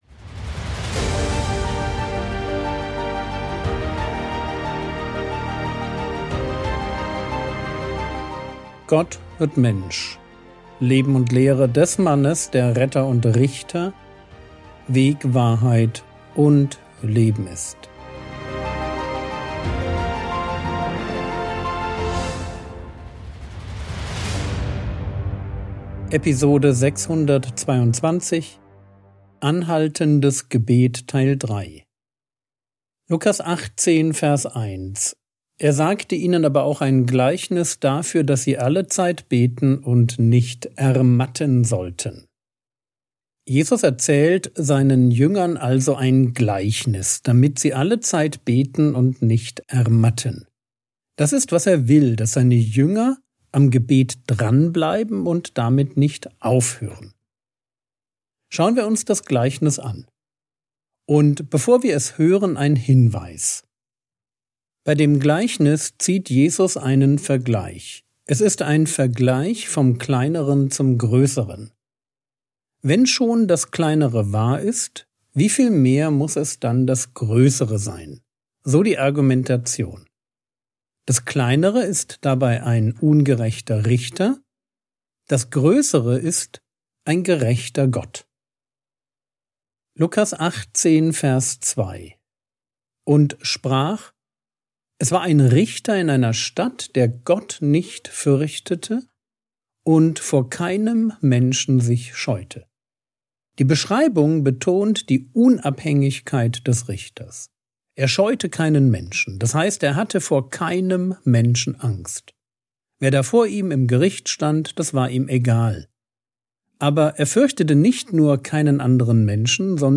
Frogwords Mini-Predigt Podcast - Episode 622 | Jesu Leben und Lehre | Free Listening on Podbean App